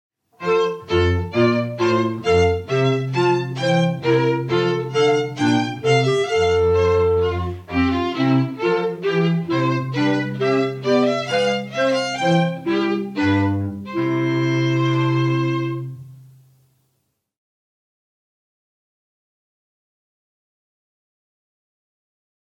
Violin, Clarinet, Cello:  7,